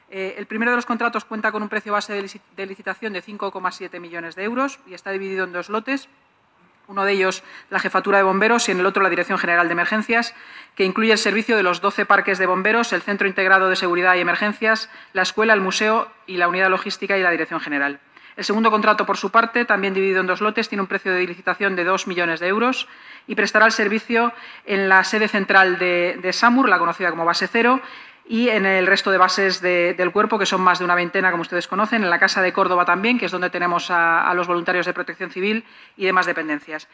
Nueva ventana:La portavoz municipal especifica los dos contratos de limpieza aprobados hoy y a qué edificios se destinarán